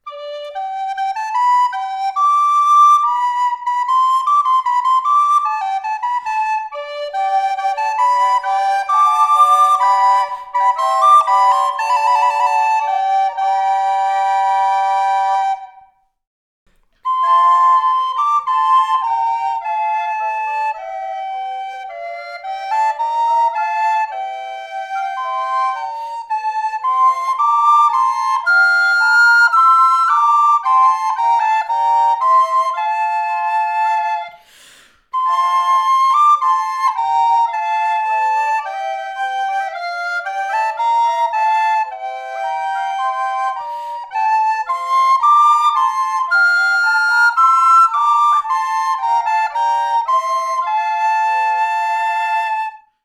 … wird die Familie unterm Christbaum mit betörendenden Blockflötenklängen malträtiert. Damit in diesem Jahr nicht die lieben Kleinen den Zorn auf sich ziehen müssen, wurde die JO-Blockflötenselbsthilfegruppe ins Leben gerufen.